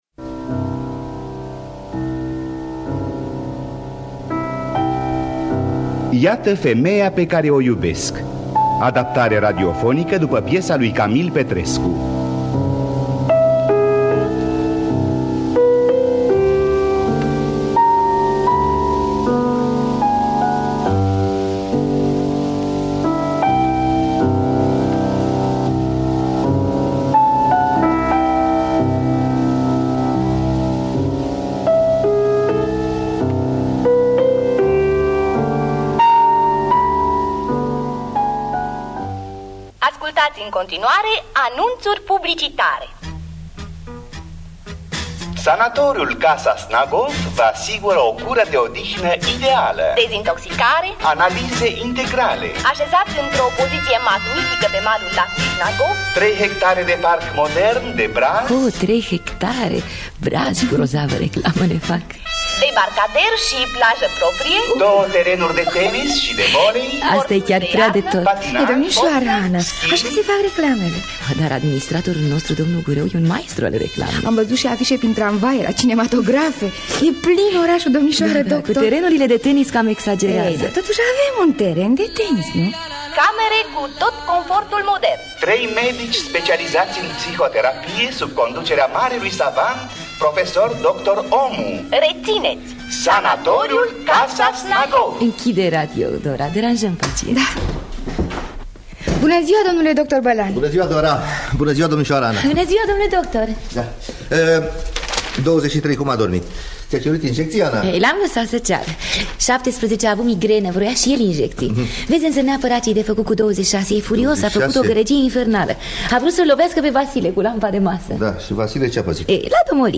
Adaptarea radiofonică
Înregistrare din anul 1975